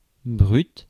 Ääntäminen
IPA : /kɹuːd/